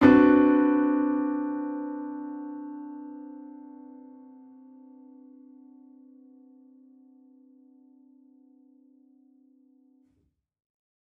Index of /musicradar/gangster-sting-samples/Chord Hits/Piano
GS_PiChrd-D6min7.wav